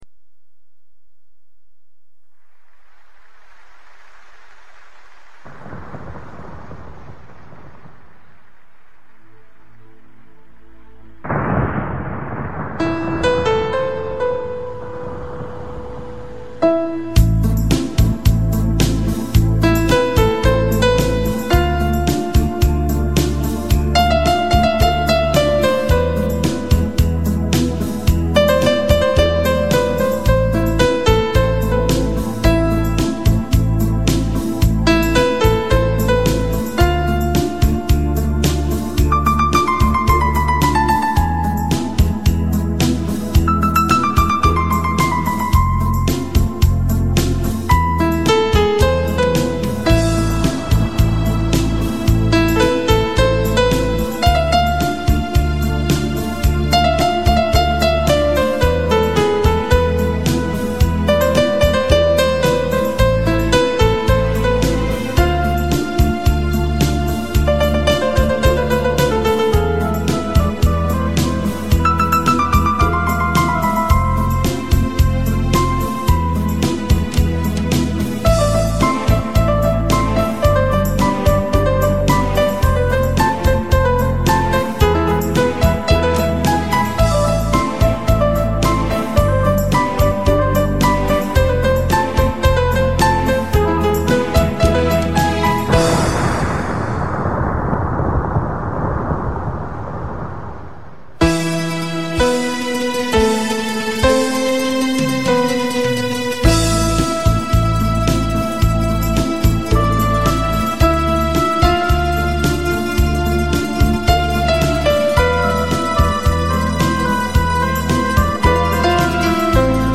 Пианино - Музыка души.mp3